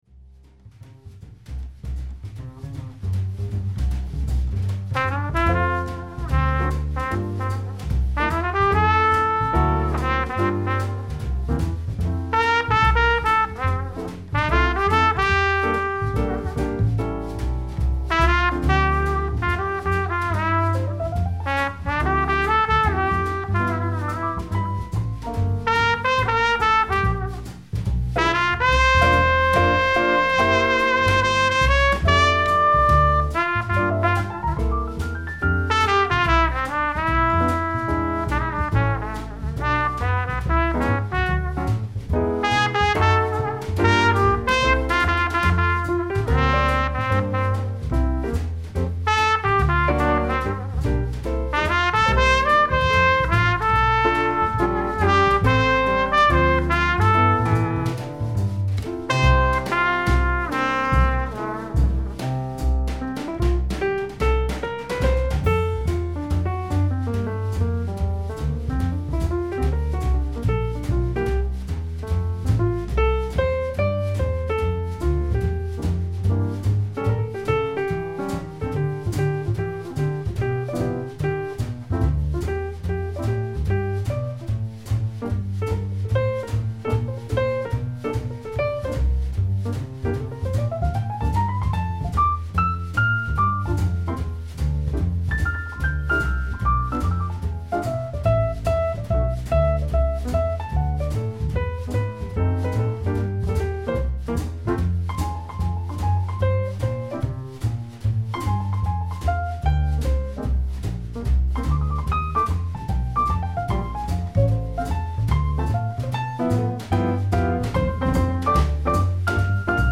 This is a great collection of swing and ballad standards.